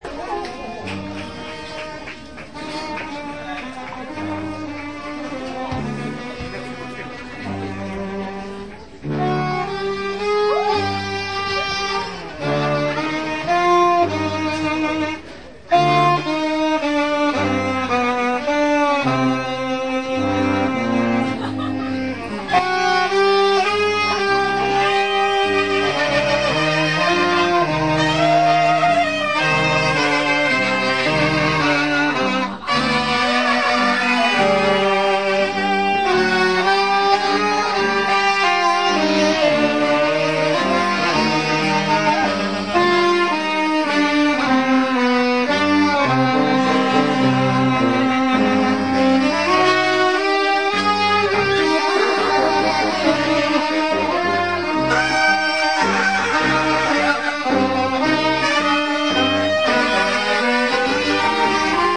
03.04.2002, Loft Shinjuku, Tokyo, Japan
big band of 14 people